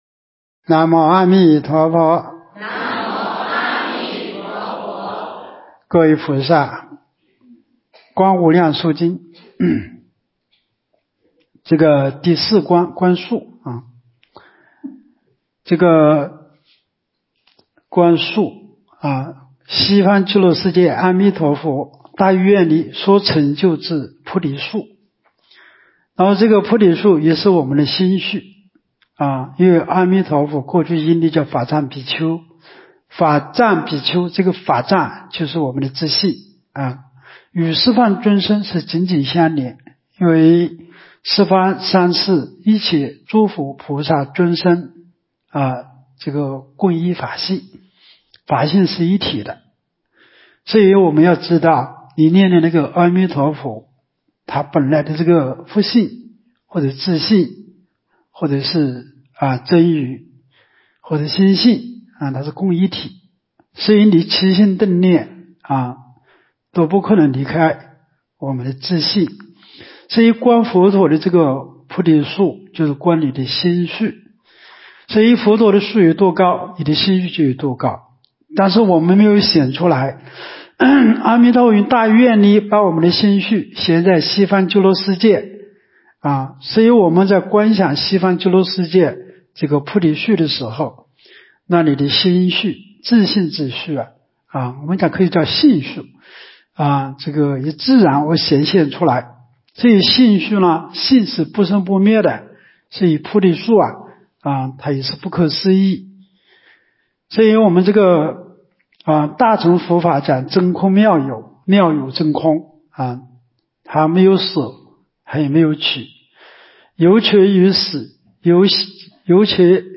无量寿寺冬季极乐法会精进佛七开示（21）（观无量寿佛经）...